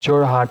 Jorhat (/ˈɔːrhɑːt/
Jorhat_Pronunciation.wav.mp3